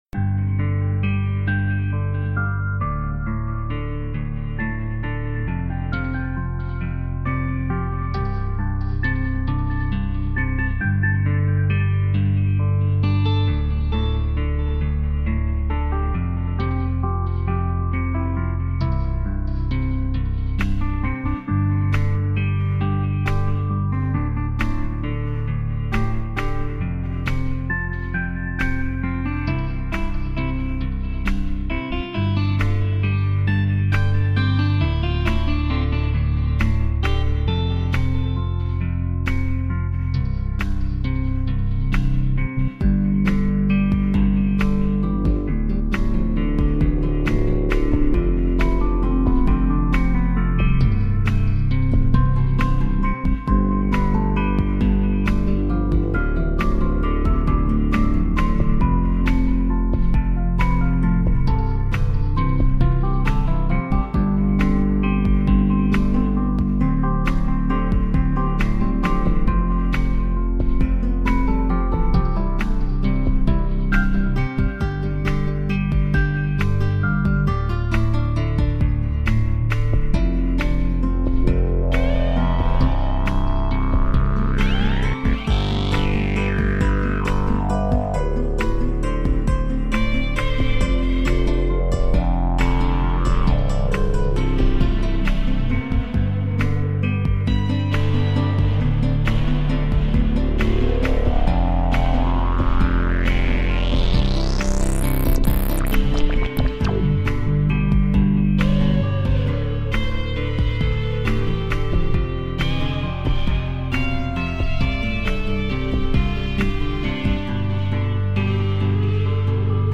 radioteatro | Radio Onda Rossa